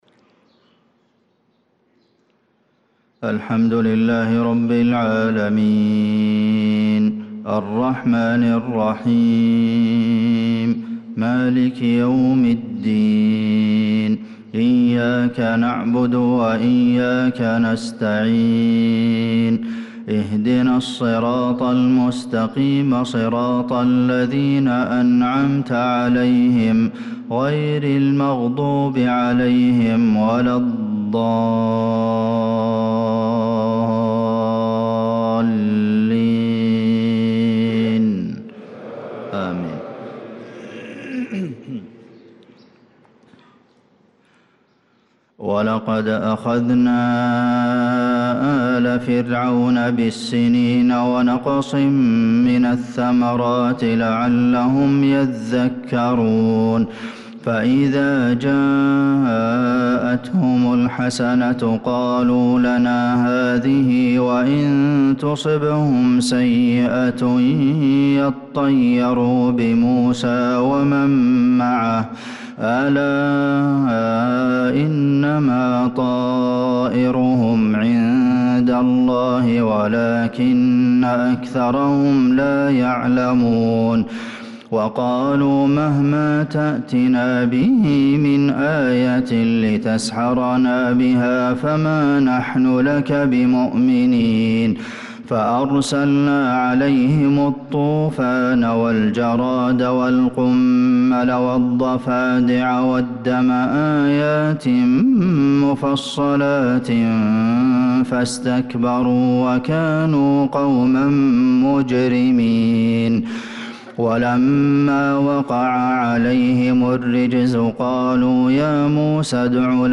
صلاة الفجر للقارئ عبدالمحسن القاسم 1 ذو القعدة 1445 هـ